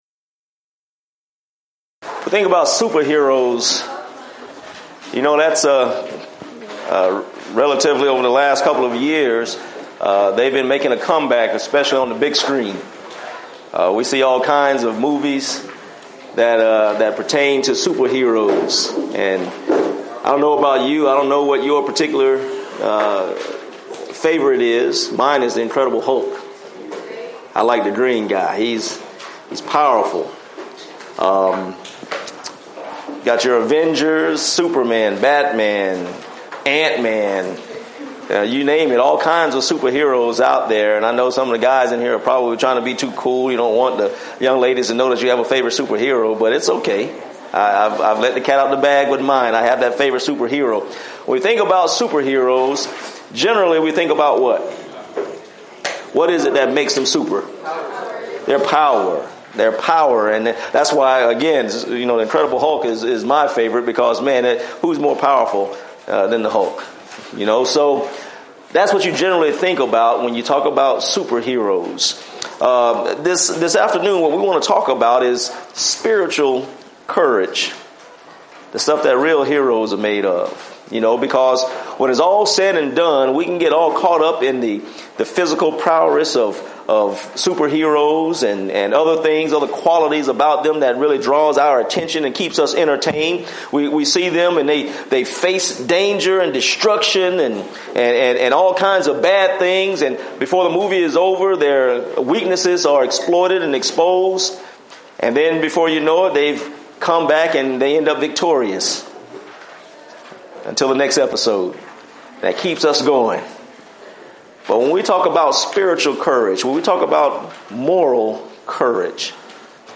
Event: 2015 Discipleship University
lecture